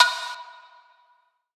MZ Snare [Yeezy].wav